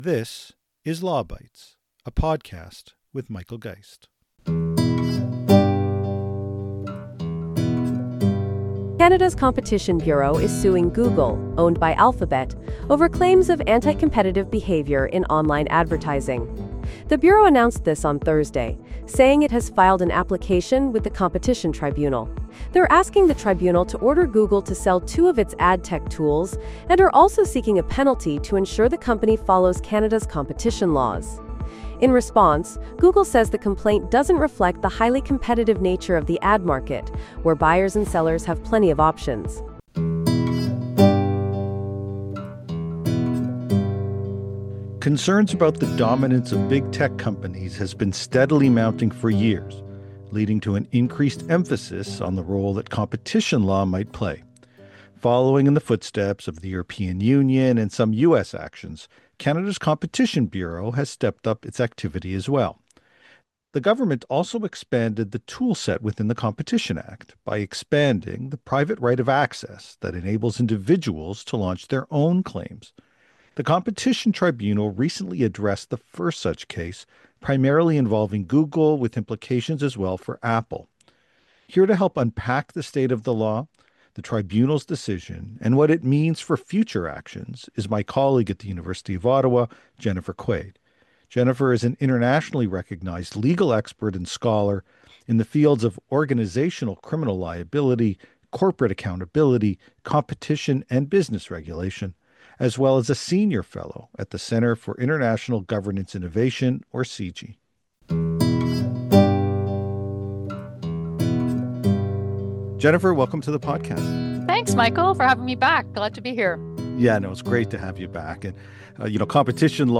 That study touched on many of the same issues as the AI consult with robust discussion on transparency, regulation, and navigating potentially conflicting policy objectives. This week’s Law Bytes podcast offer up a taste of both with the key issues raised in the submission and clips from the committee appearance including my opening statement and exchanges with multiple MPs.